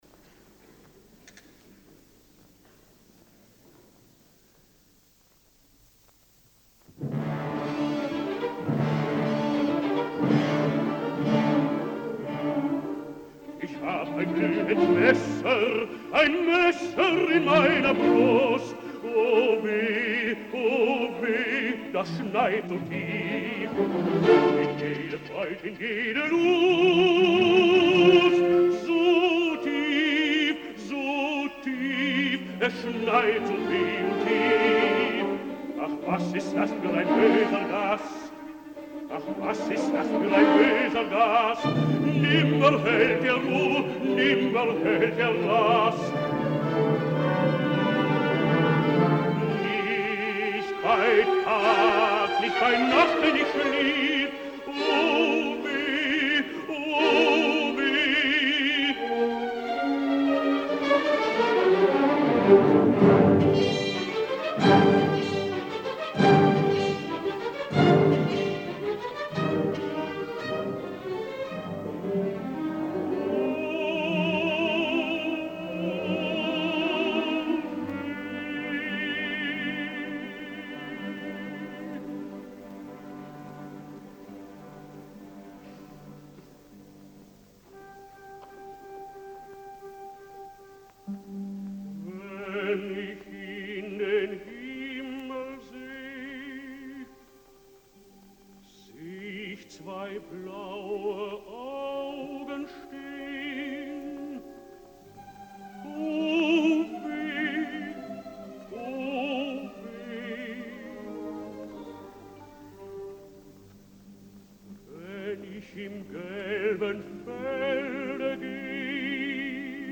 Mahler – Lieder eines fahrenden Gesellen Concertgebouw Orchestra Amsterdam
Live recording Soloists: Hermann Schey, tenor